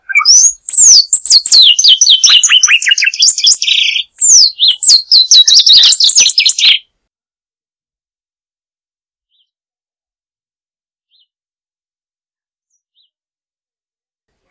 Molothrus bonariensis - Tordo común
tordopicofino.wav